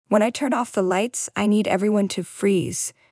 The adult pairs a simple direction with a visual cue (for example, a picture, gesture or motion) to prevent a behavior from occurring and guides the child to an alternative choice.
REDIRECTION-VERBAL-WITH-CUE.mp3